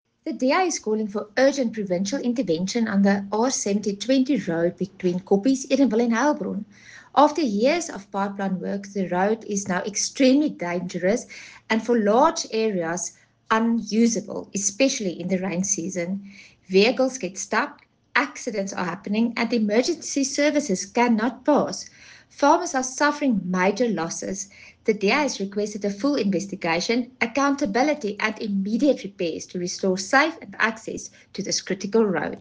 Afrikaans soundbites by Cllr Carina Serfontein and